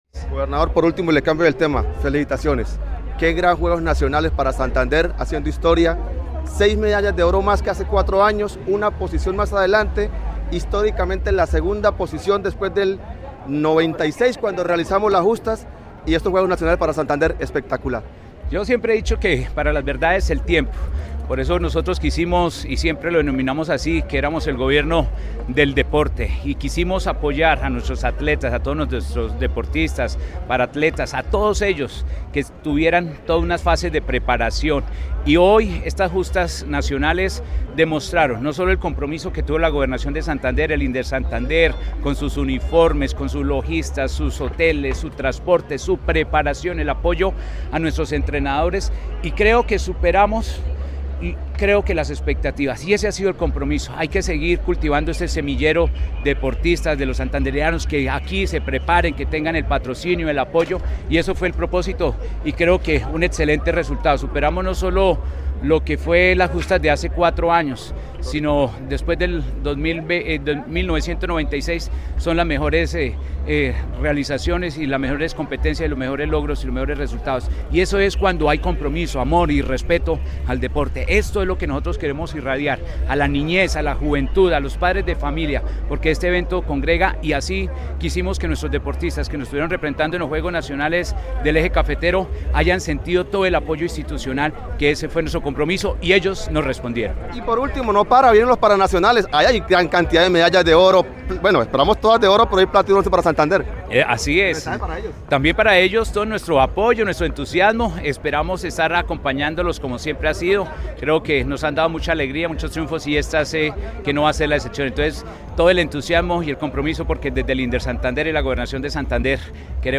Audio gobernador de Santander, Mauricio Aguilar Hurtado
Full-Gobernador-Mauricio-Aguilar-habla-de-los-Juegos-Nacionales-para-Santander.mp3